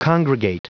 Prononciation du mot congregate en anglais (fichier audio)
Prononciation du mot : congregate